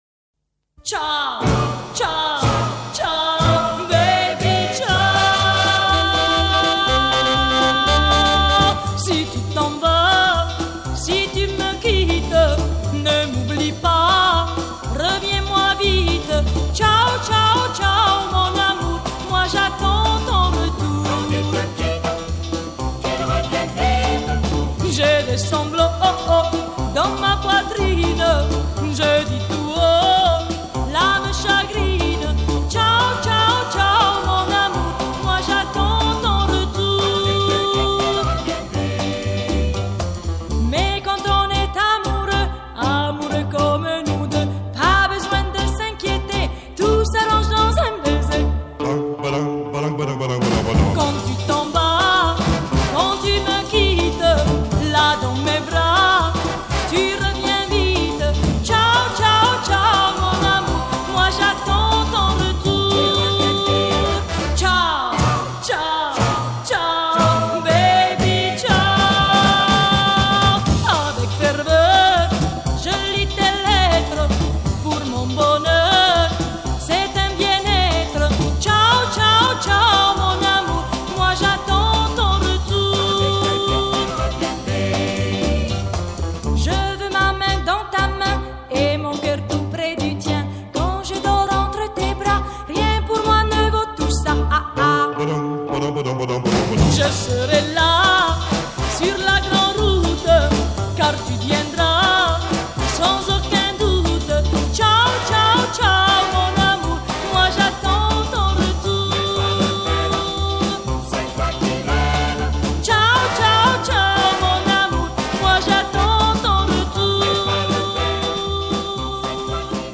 Chanson, Pop